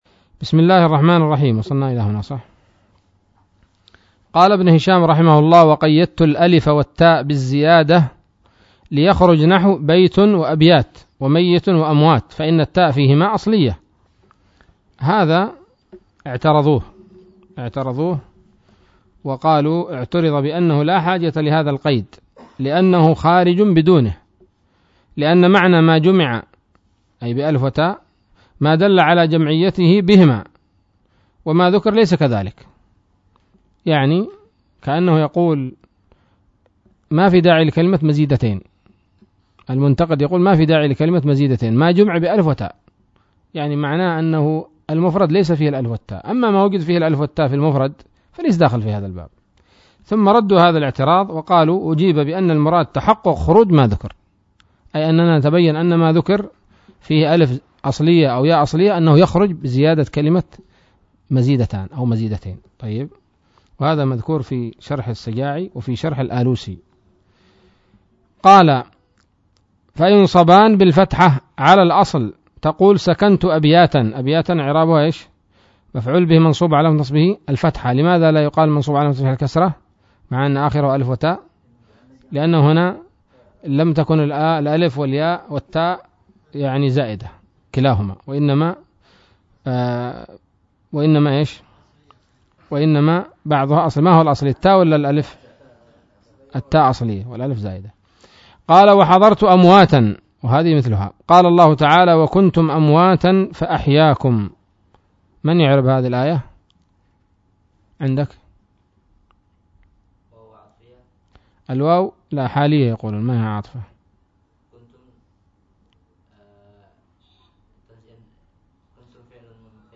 الدرس الثالث والعشرون من شرح قطر الندى وبل الصدى